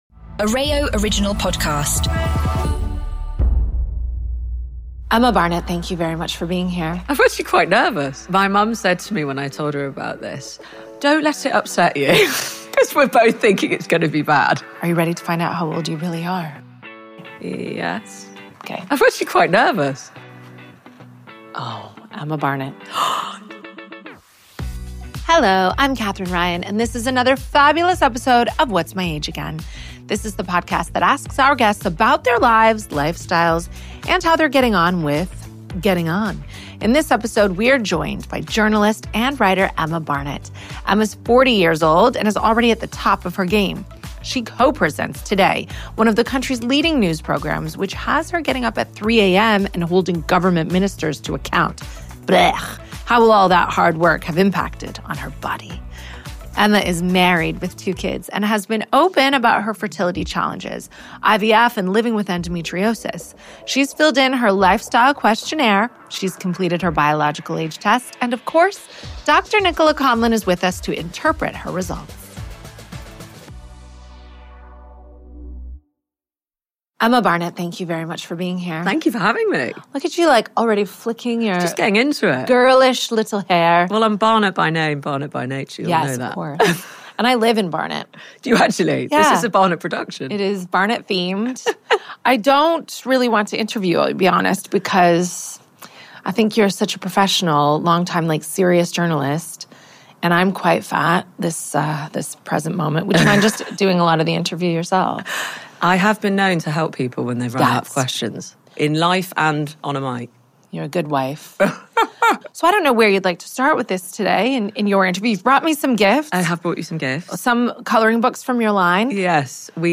Emma’s used to asking the questions but in this frank, thought-provoking conversation, Emma talks openly about the impact of 6 rounds of IVF followed by early years parenting, her endometriosis, her more recent diagnosis of adenomyosis, and the lifestyle changes she is trying to alleviate her symptoms.